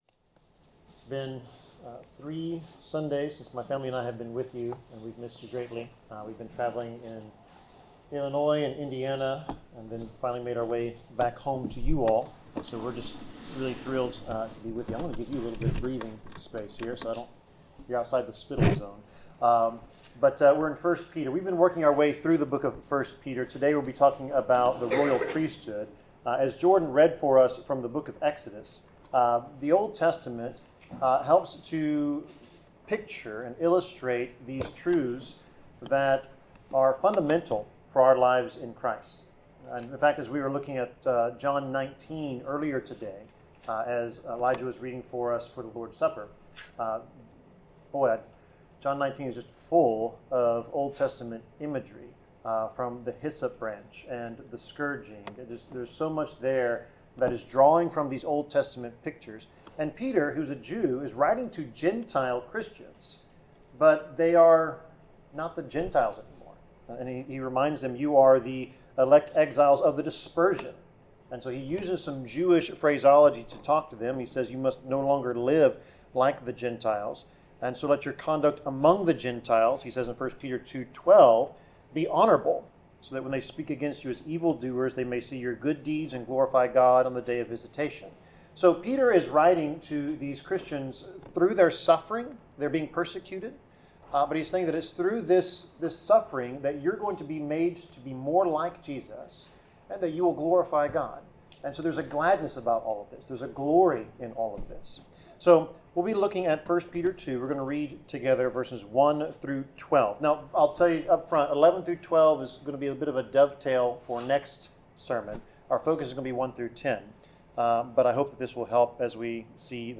Passage: 1 Peter 2:1-12 Service Type: Sermon